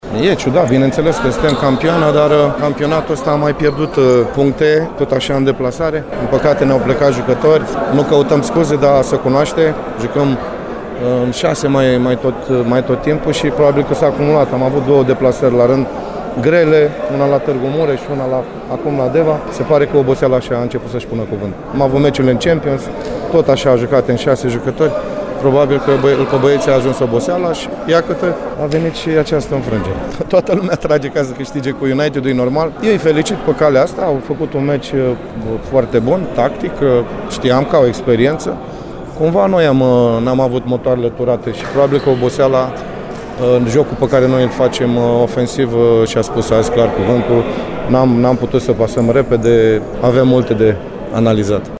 Reacțiile de după meci au fost ”culese” de la fața locului